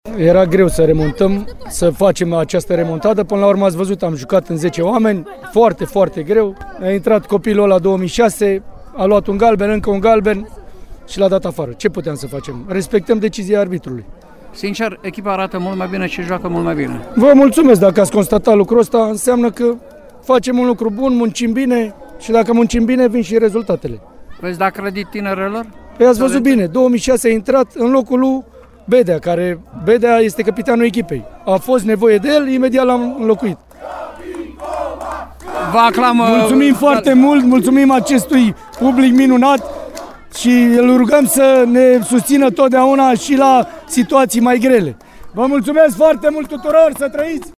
a fost scandat pe parcursul interviului